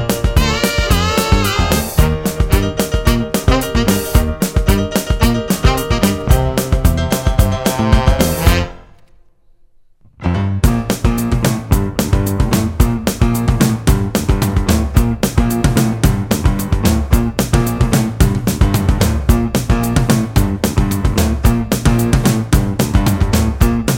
No Backing Vocals Soundtracks 2:56 Buy £1.50